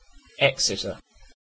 Exeter (/ˈɛksɪtər/
En-uk-Exeter.ogg.mp3